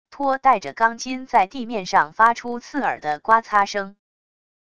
拖带着钢筋在地面上发出刺耳的刮擦声wav音频